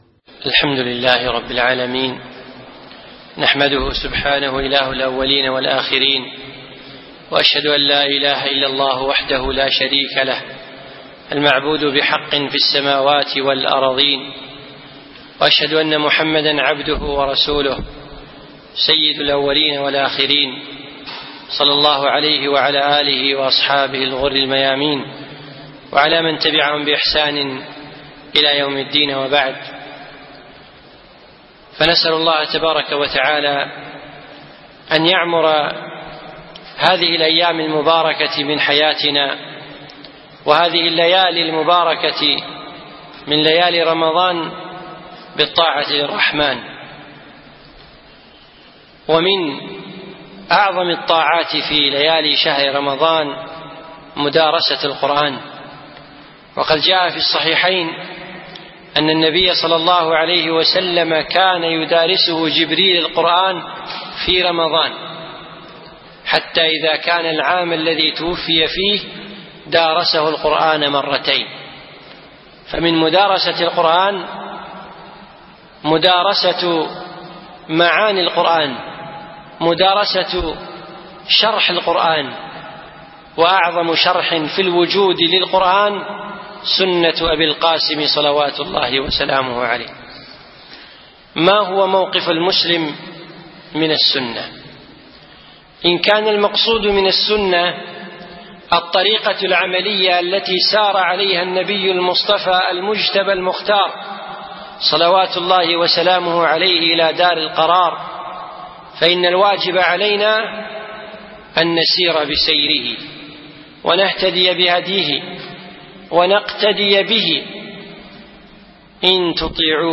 من دروس الشيخ حفظه الله في دولة الإمارات